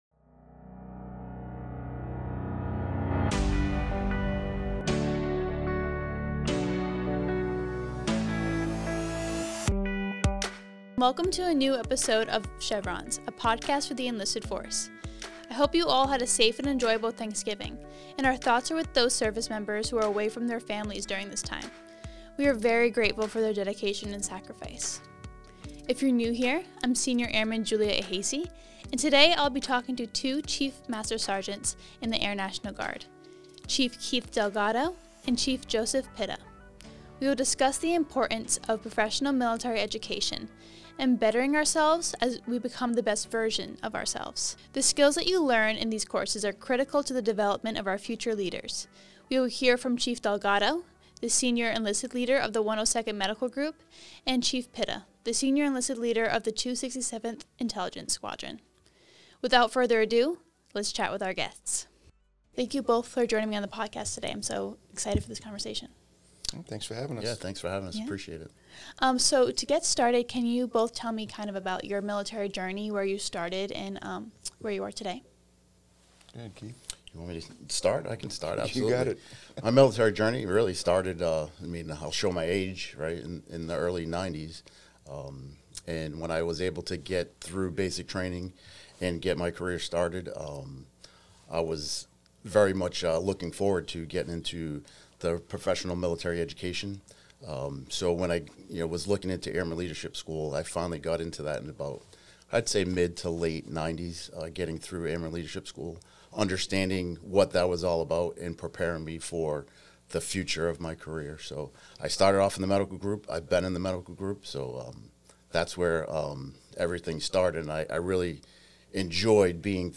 In this episode, we talk to two Chief Master Sergeants in the Air National Guard